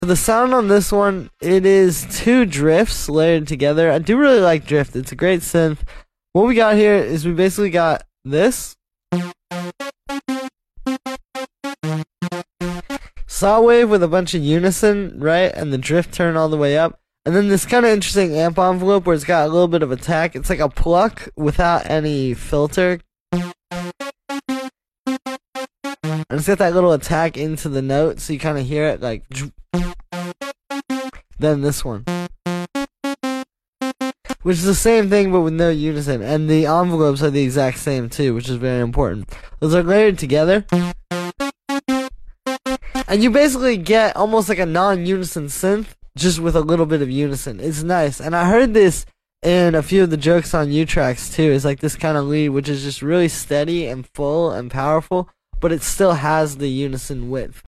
Two Drifting Saws Fused For Sound Effects Free Download